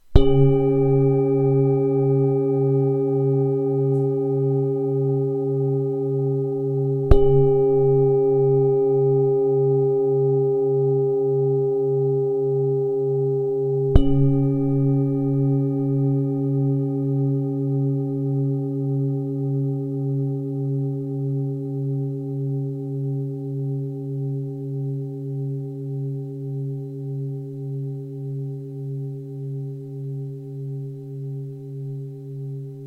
Zdobená tibetská mísa C#3 26cm
Nahrávka mísy úderovou paličkou:
Tahle mísa rezonuje v tónech kořenové čakry.